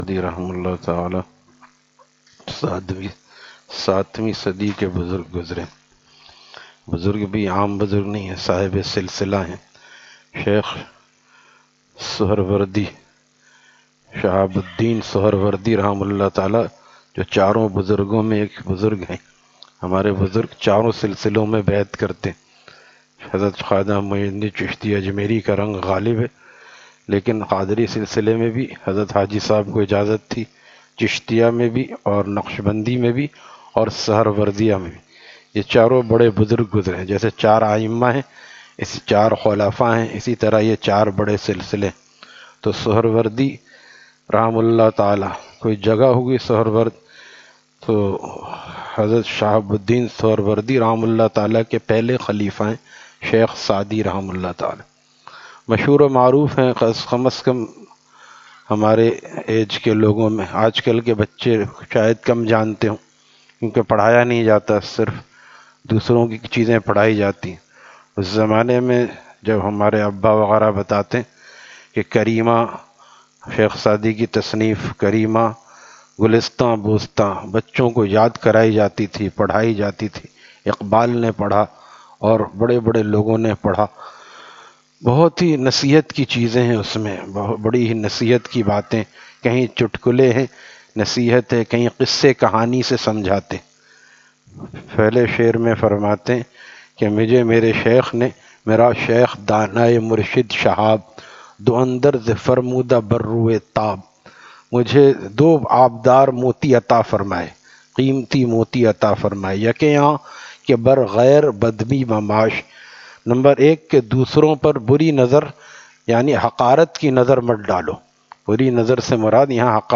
Taleem After Fajar at Jama Masjid Gulzar e Muhammadi, Khanqah Gulzar e Akhter, Sec 4D, Surjani Town